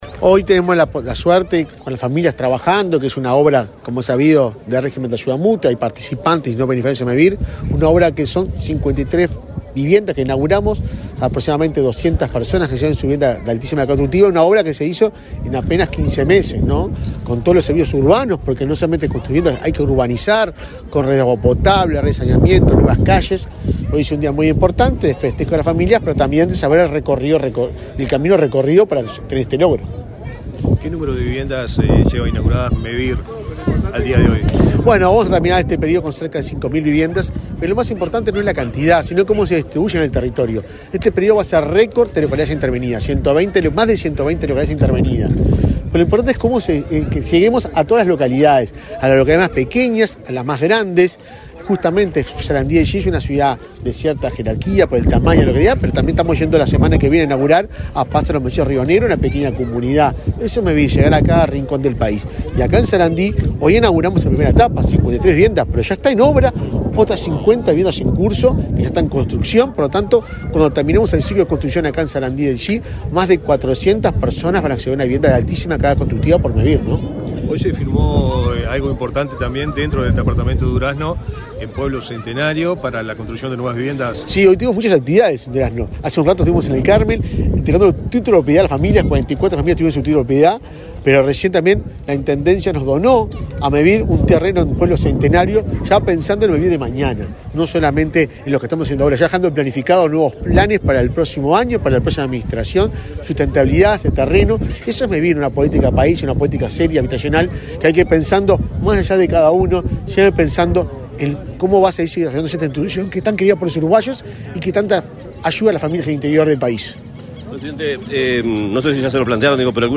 Este miércoles 25, el presidente de Mevir, Juan Pablo Delgado, fue entrevistado para medios periodísticos en Durazno, antes de inaugurar 54 soluciones